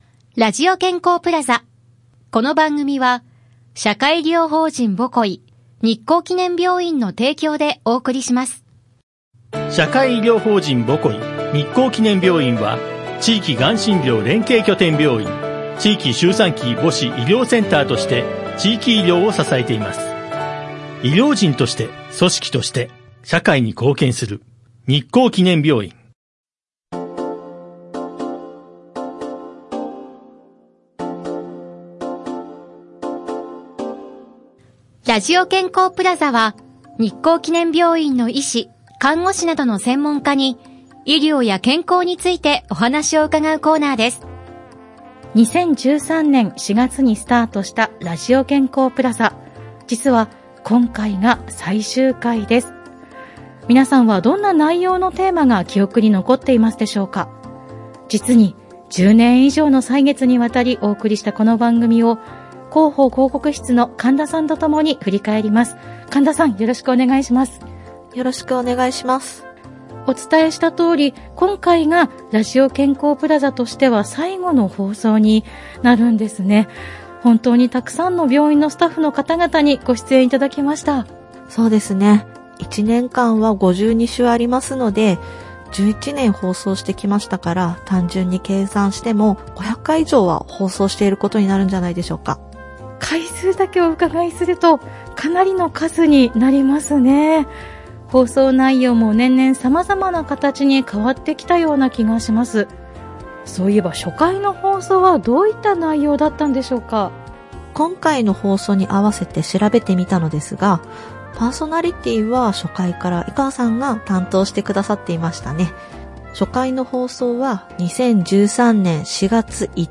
室蘭市のコミュニティFM『FMびゅー』から、様々な医療専門職が登場して、医療・健康・福祉の事や病院の最新情報など幅広い情報をお届けしています。